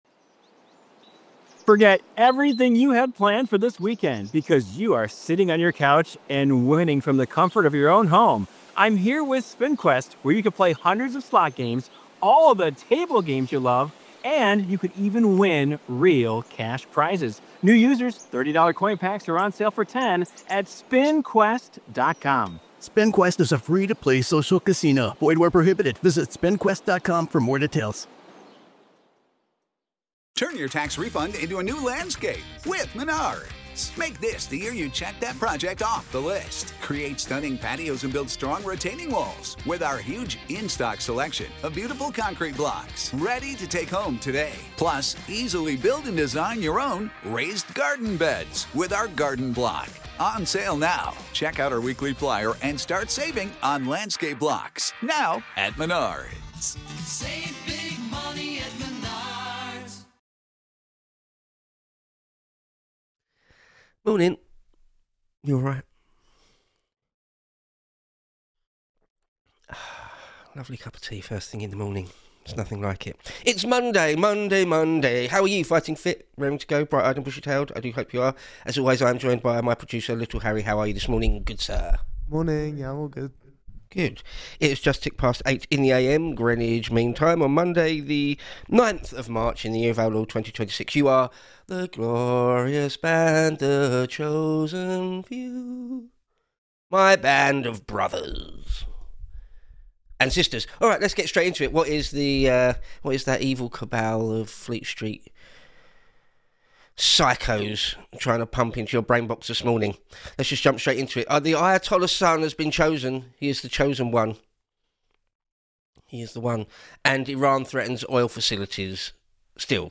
Live 8-9am GMT on weekdays.